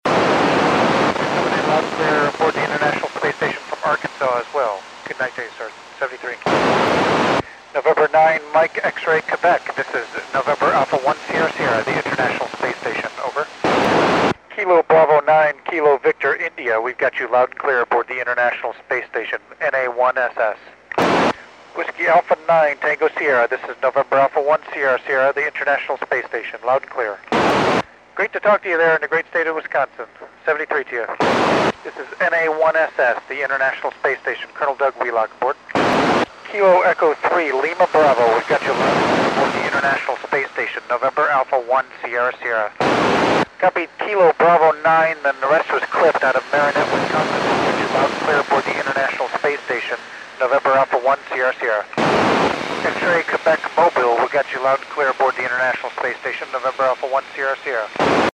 NA1SS (Col. Doug Wheelock) working U.S. Stations on 01 September 2010 at 2355 UTC
Col. Doug Wheelock (NA1SS) wks U.S. stations